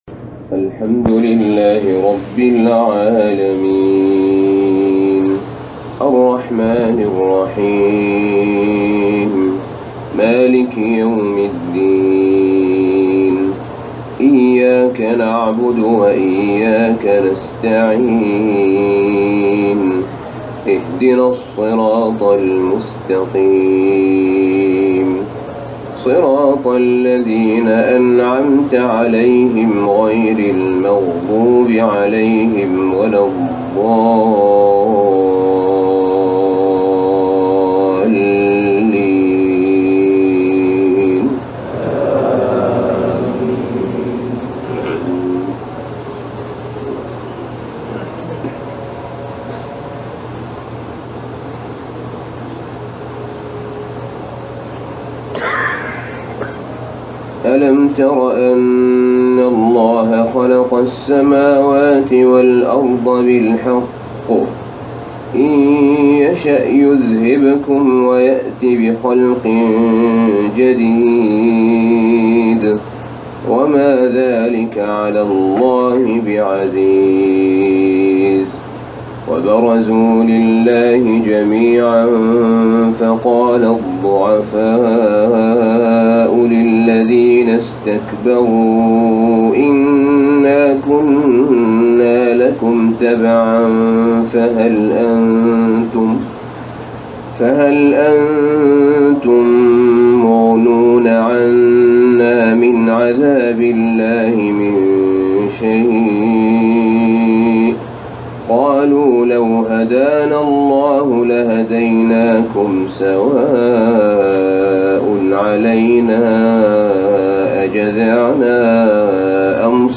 صلاة الفجر آخر أيام التشريق 1437هـ من مشعر منى > تلاوات أئمة الحرمين من المشاعر المقدسة > المزيد - تلاوات الحرمين